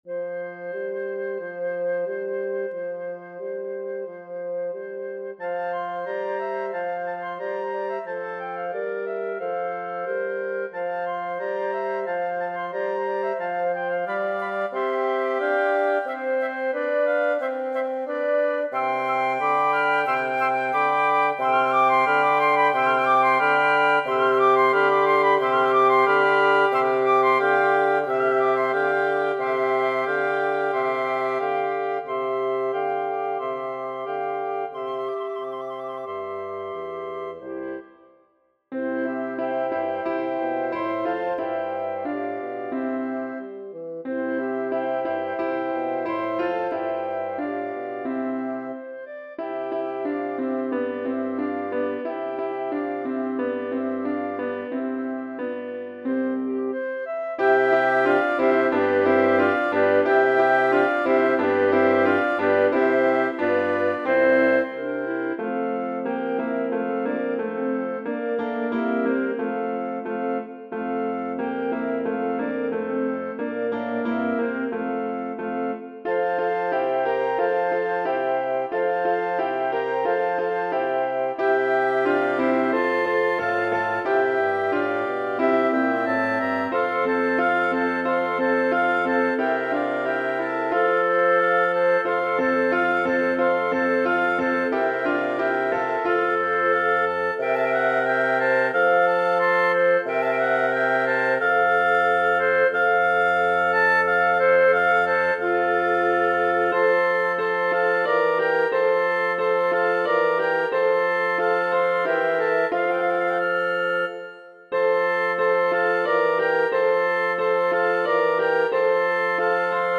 České koledy pro 3 příčné flétny a bas
Jsou to opravdu jednoduché harmonie, ale tak to Stecker napsal.